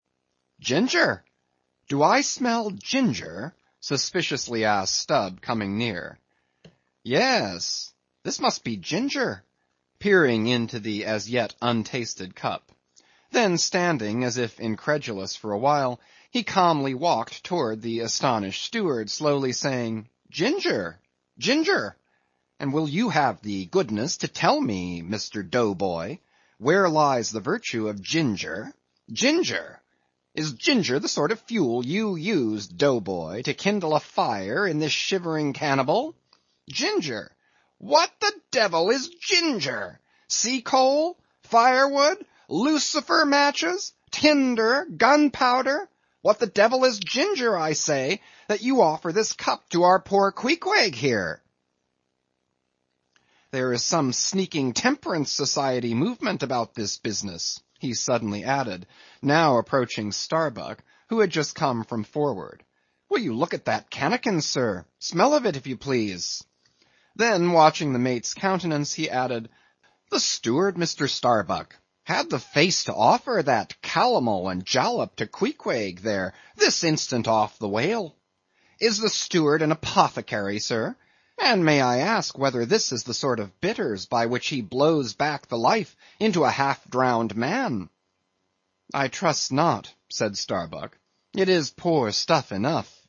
英语听书《白鲸记》第658期 听力文件下载—在线英语听力室